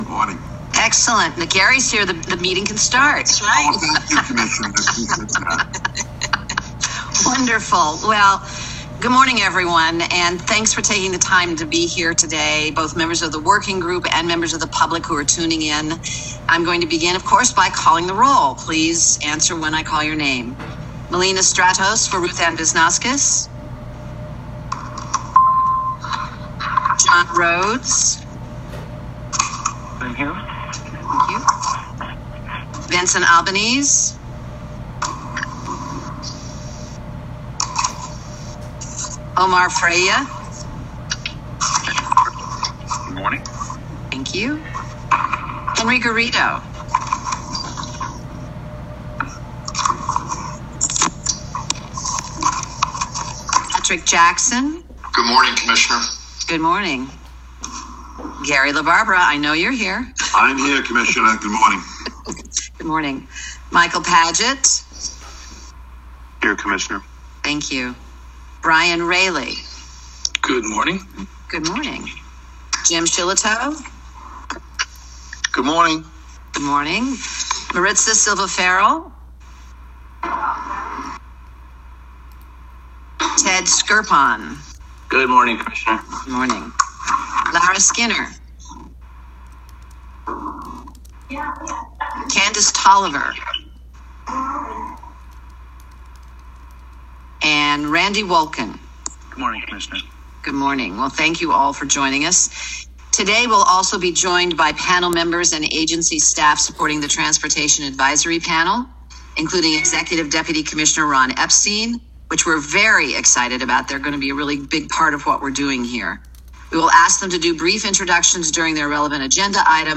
Listen to recording of meeting Climate Action Council Just Transition Working Group February 23, 2021 &nbsp &nbsp